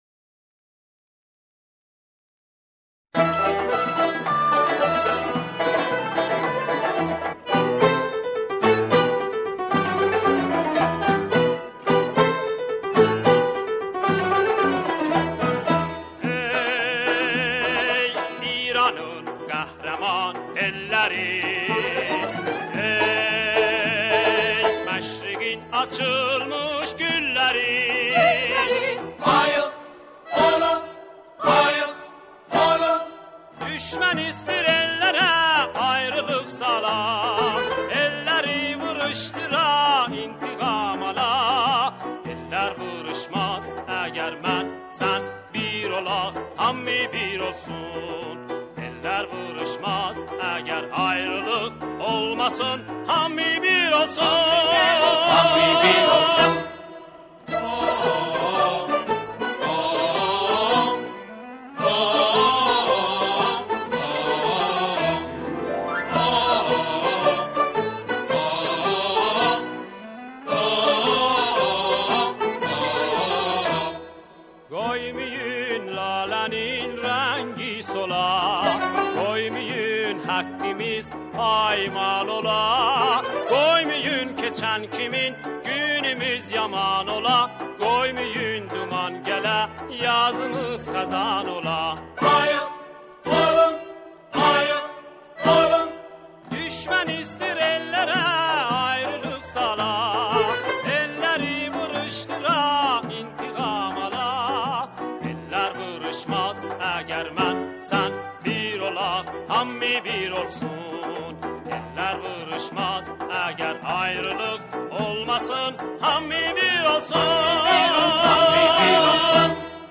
همخوانی شعری
گروهی از جمعخوانان